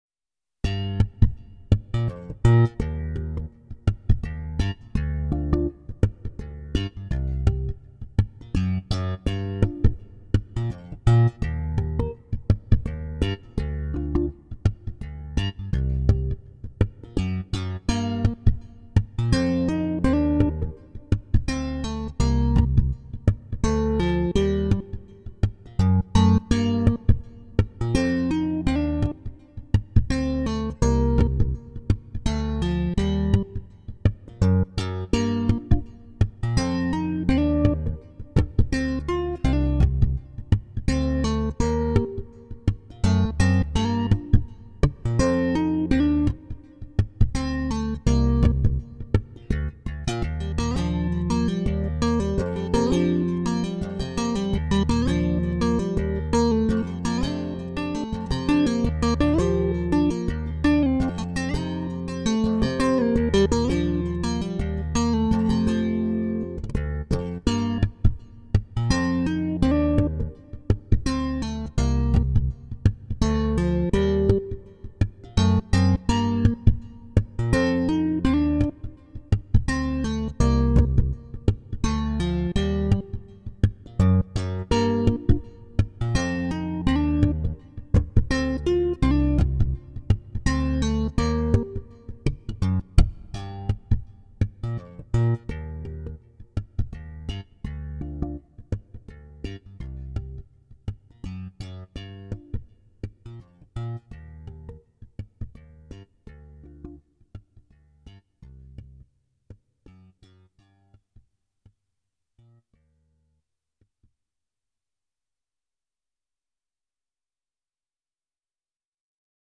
All of the samples were DI'd (direct injected) for sampling.